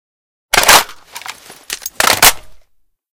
reload1.ogg